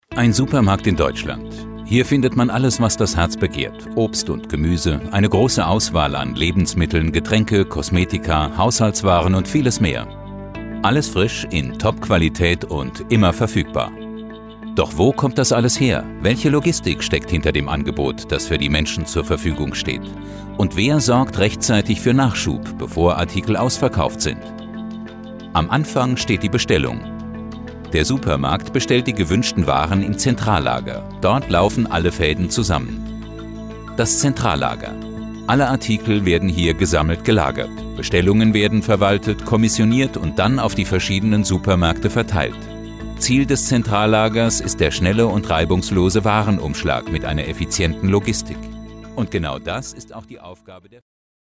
Deutscher Sprecher.
Sprechprobe: Industrie (Muttersprache):
german voice over artist